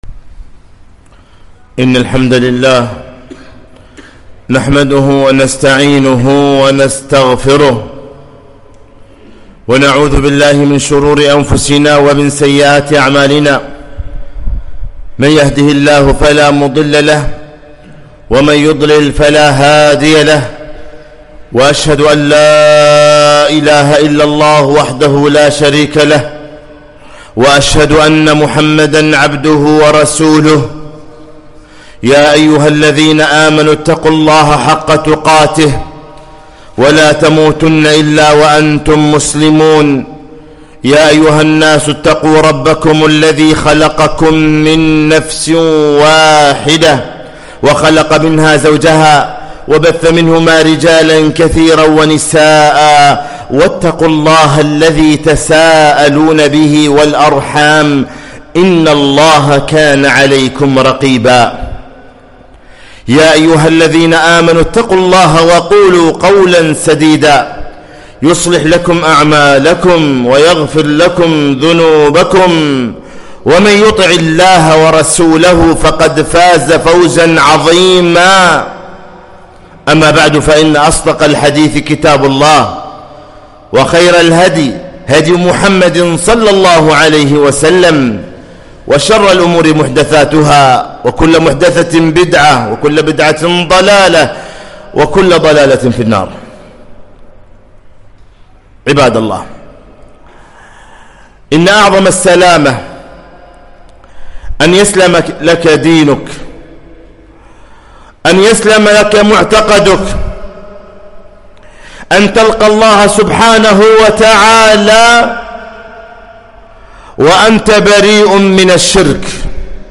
خطبة - (حذارِ من الإثم العظيم)